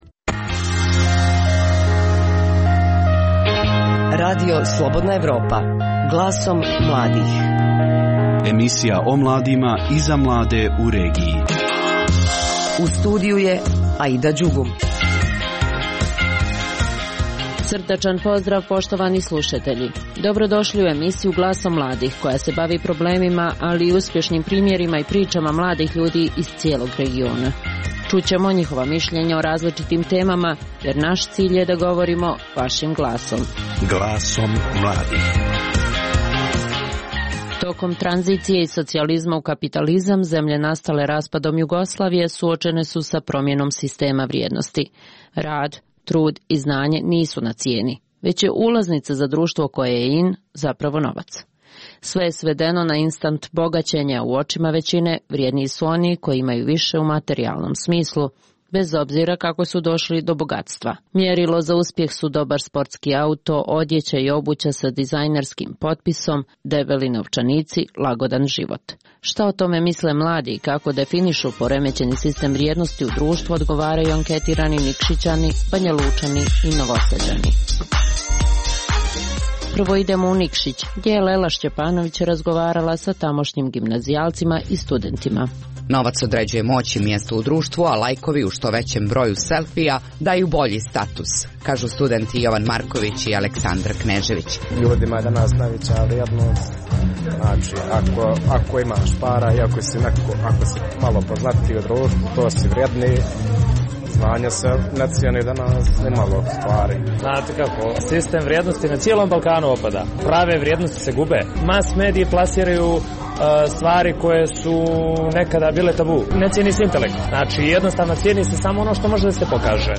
Šta o tome misle mladi i kako definišu poremećini sistem vrijednosti u društvu odgovaraju anketirani Nikšićani, Banjalučani i Novosađani. Govorimo i o tome koliko su rijaliti programi popularni u Srbiji, te da li su mladi zainteresovani za zanate.